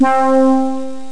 Amiga 8-bit Sampled Voice
horn.mp3